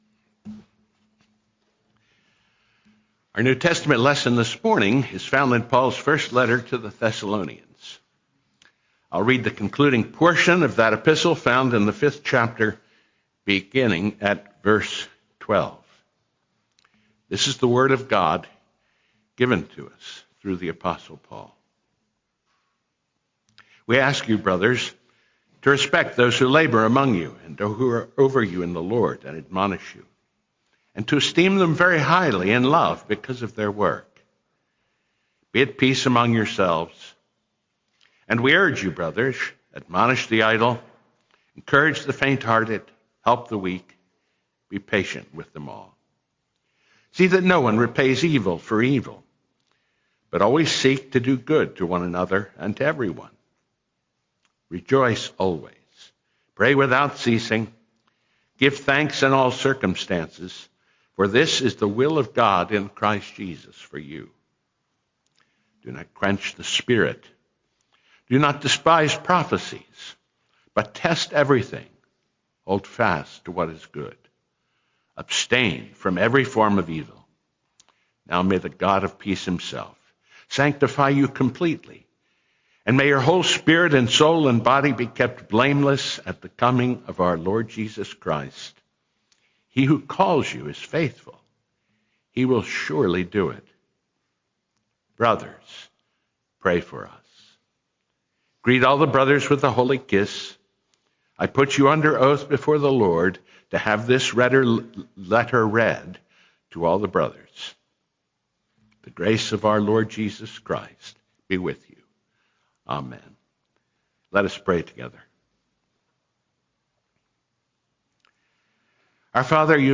march-8-2026-sermon-only.mp3